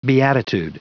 Prononciation du mot beatitude en anglais (fichier audio)
Prononciation du mot : beatitude